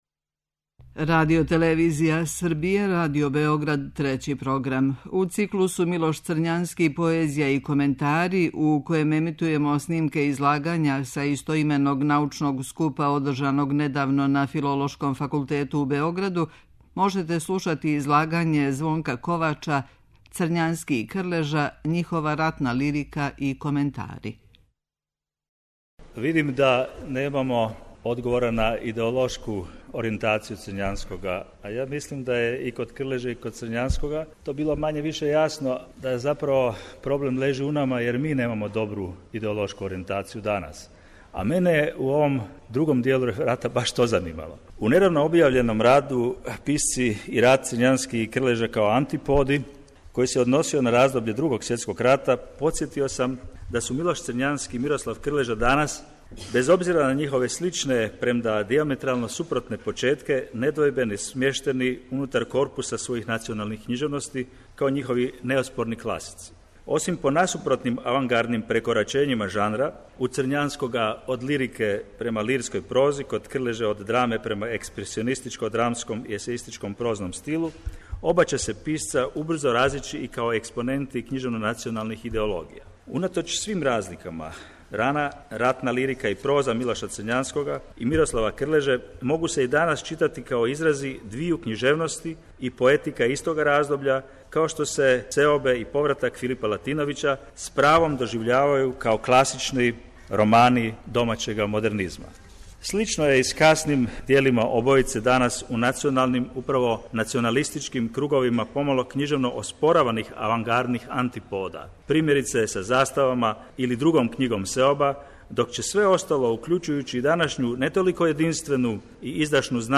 У циклусу МИЛОШ ЦРЊАНСКИ: ПОЕЗИЈА И КОМЕНТАРИ, у среду 26. фебруара и 5. марта, моћи ћете да пратите снимке излагања са истоименог научног скупа који је крајем прошле године одржан на Филолошком факултету у Београду.